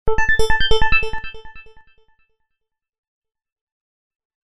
messagealert4.mp3